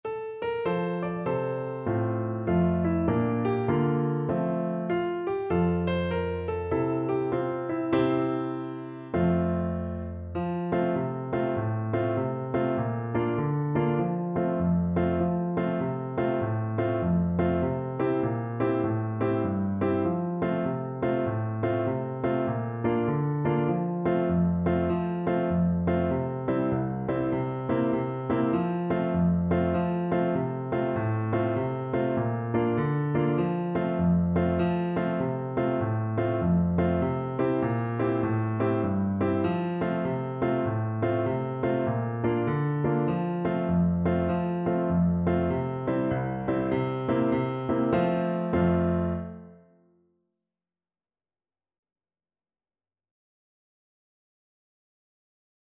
4/4 (View more 4/4 Music)
With a swing =132
Traditional (View more Traditional Voice Music)
Sea Shanties for Voice